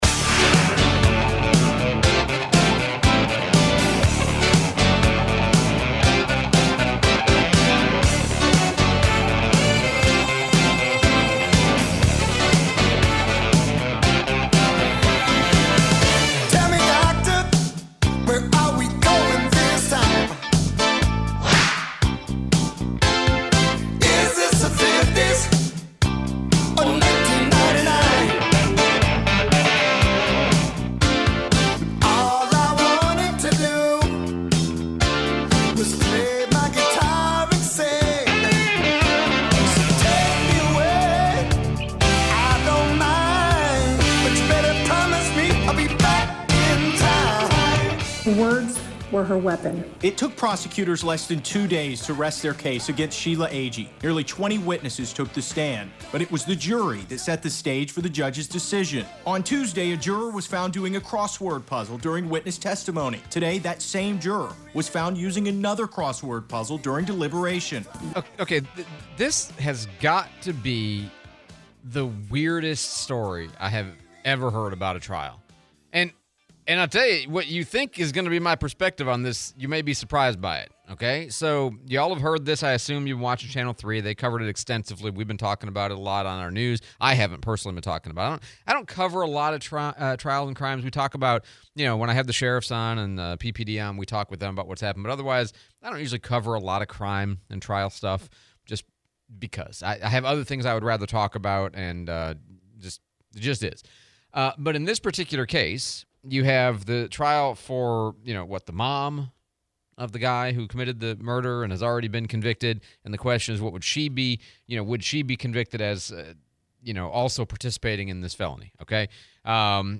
Interview with Senator Rick Scott (replay)